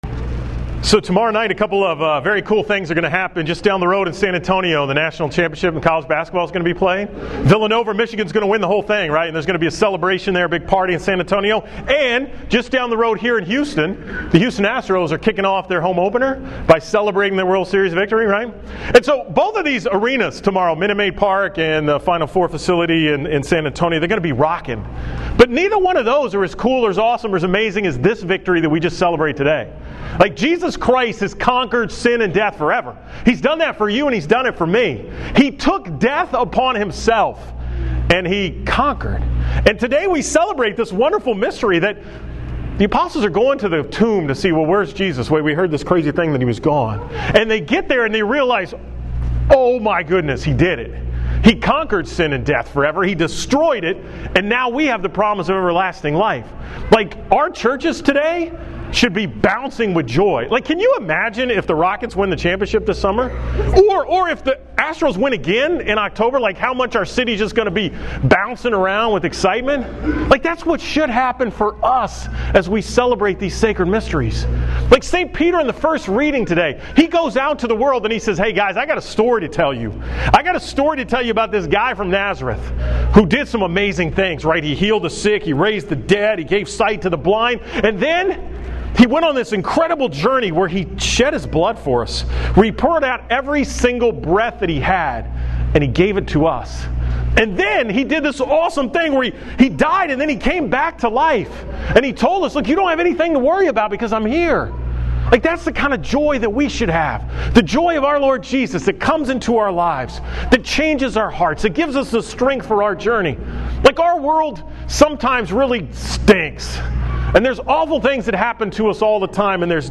From Easter Mass at St. Michael's on April 1, 2018